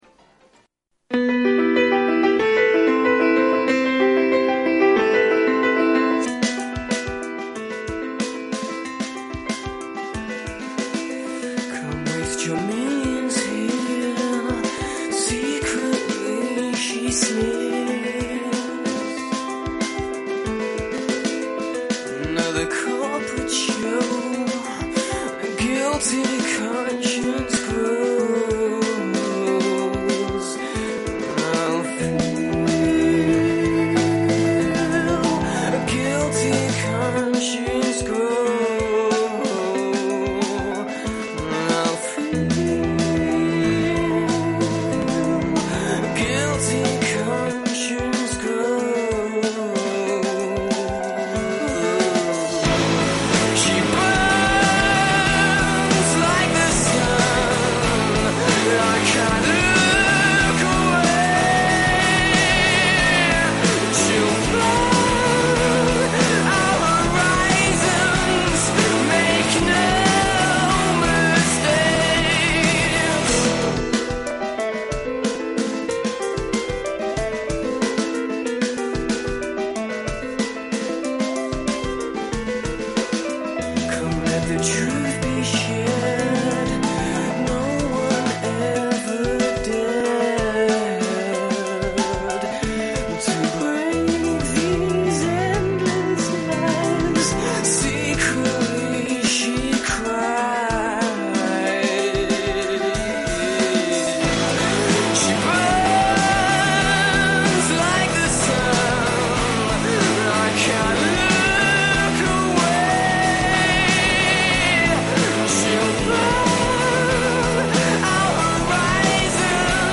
Il Vicesindaco di Arezzo Gianfrancesco Gamurrini ospite nella trasmissione “Arezzo Svegliati” di giovedi 31 agosto in diretta su RadioFly